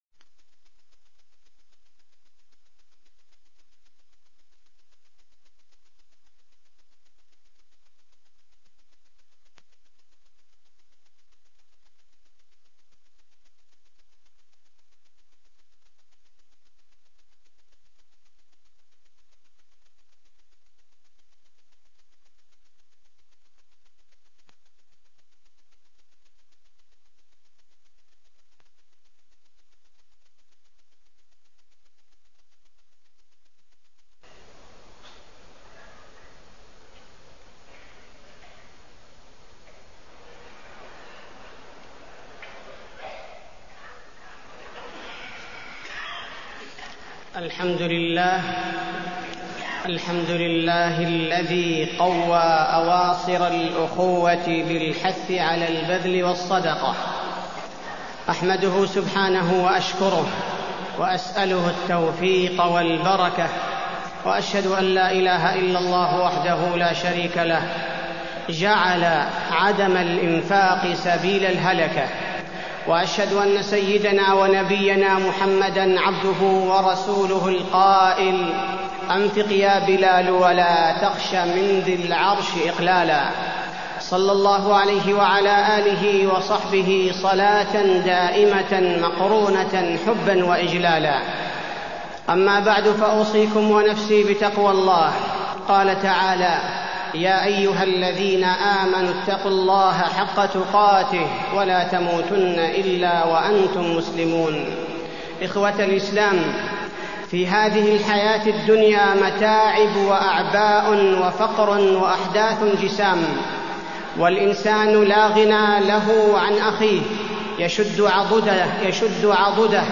تاريخ النشر ١٣ رمضان ١٤٢٤ هـ المكان: المسجد النبوي الشيخ: فضيلة الشيخ عبدالباري الثبيتي فضيلة الشيخ عبدالباري الثبيتي الصدقة